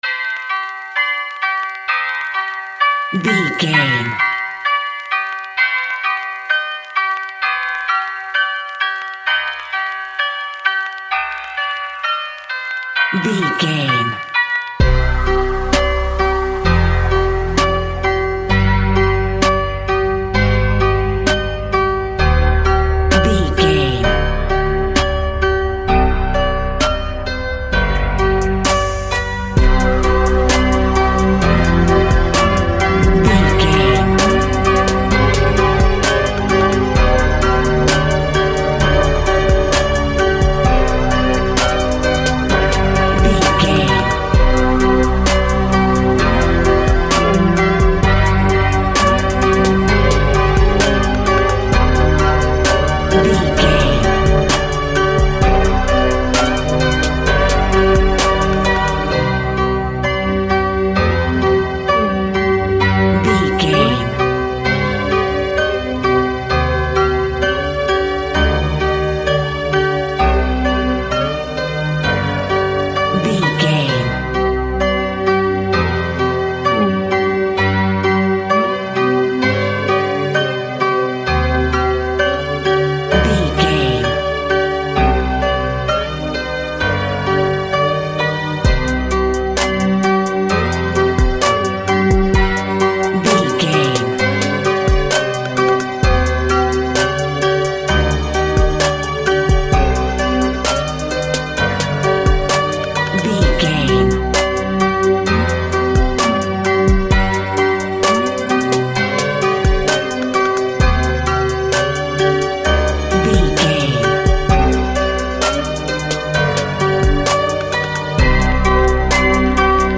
Rap Music for a Haunted Movie.
Aeolian/Minor
ominous
dark
eerie
piano
sythesizer
drum machine
horror music
horror instrumentals